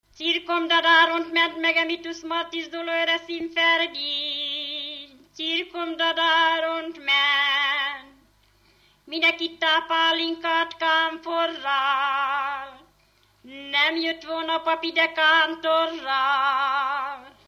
Alföld - Pest-Pilis-Solt-Kiskun vm. - Zagyvarékas
Dallamtípus: Búcsús, halottas 1
Stílus: 8. Újszerű kisambitusú dallamok
Kadencia: 1 (1) 2 1